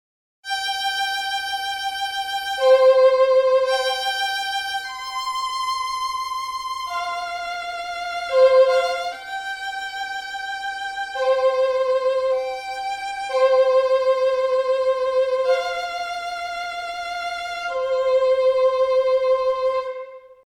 А то загрузил скрипку по умолчанию,а там печаль...(см пример срипка синематик) Вложения скрипка синематик.mp3 скрипка синематик.mp3 805,3 KB · Просмотры: 1.357